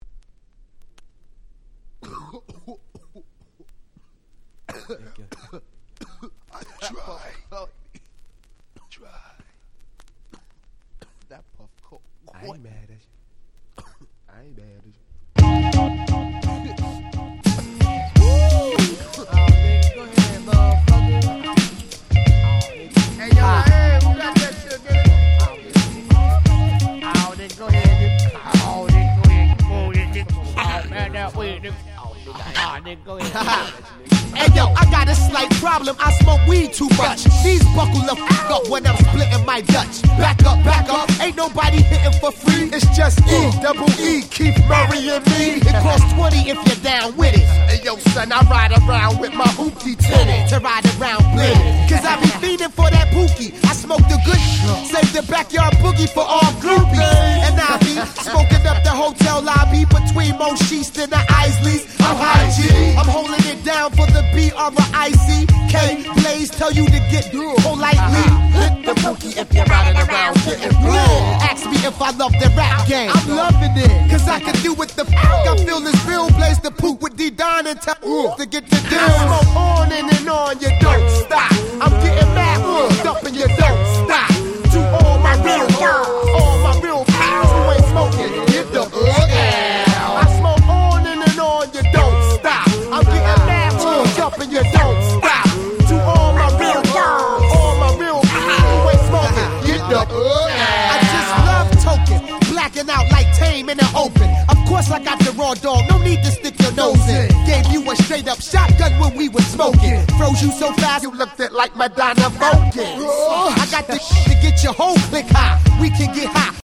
97' Smash Hit Hip Hop !!
Boom Bap